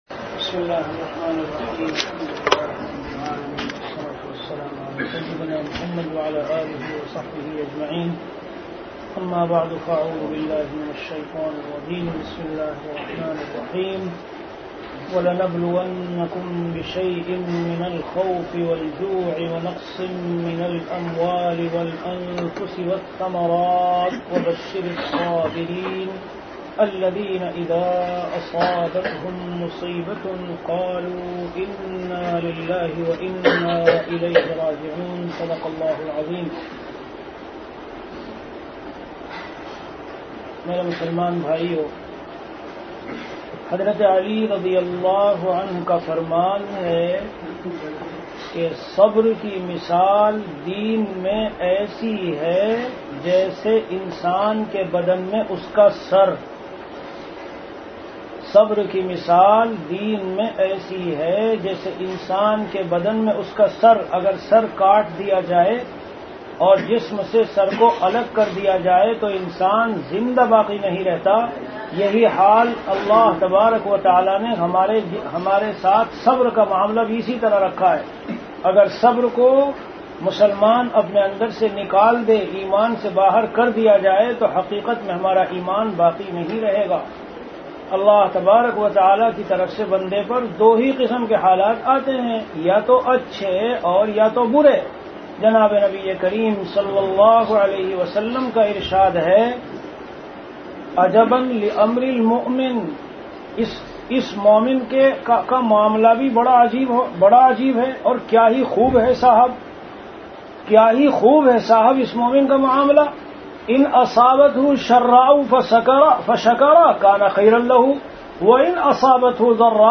Delivered at Qatar.
Bayanat
After Isha Prayer